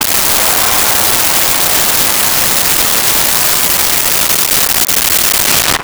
Large Crowd Applause 04
Large Crowd Applause 04.wav